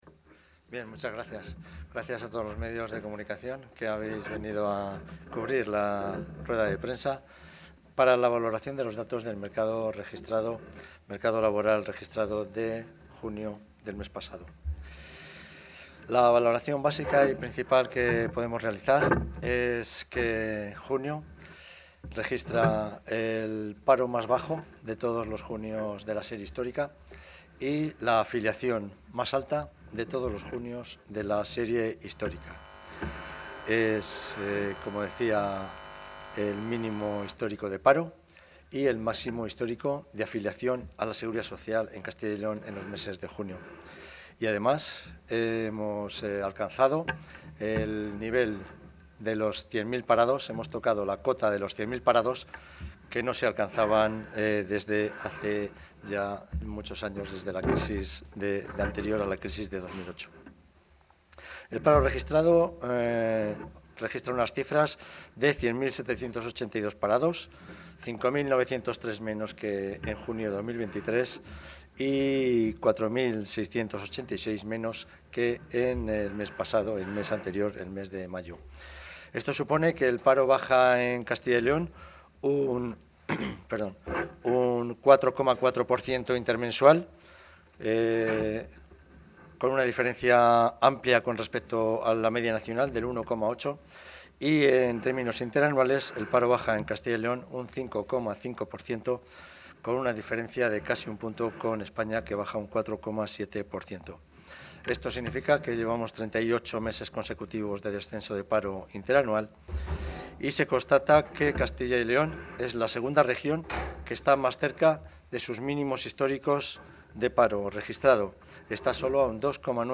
El consejero de Industria, Comercio y Empleo, Mariano Veganzones, ha valorado hoy los datos de paro registrado correspondientes al mes de junio de 2024.